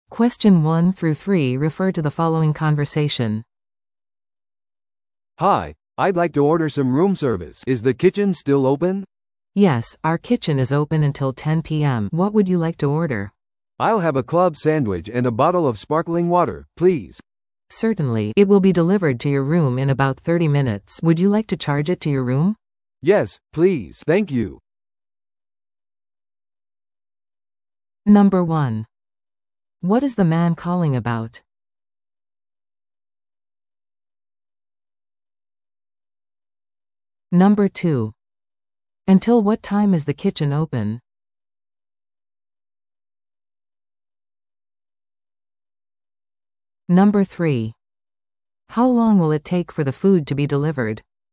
No.1. What is the man calling about?